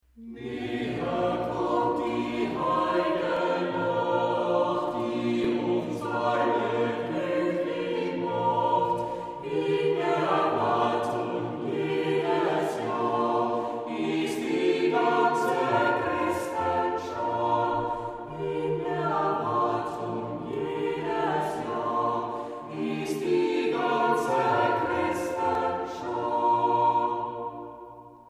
contribute to a contemplative atmosphere